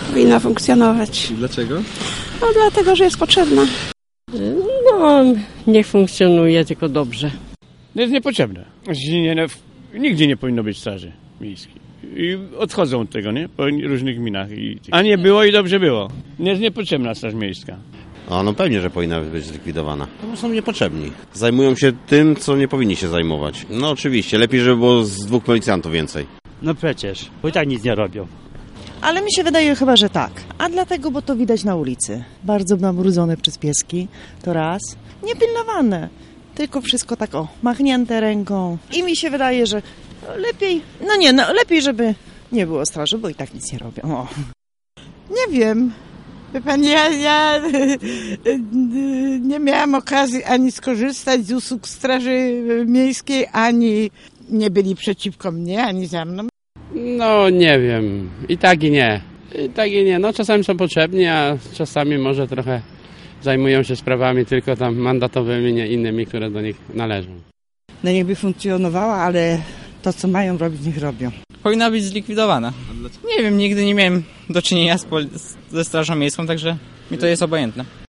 1-mieszkanc-straz-miejska.mp3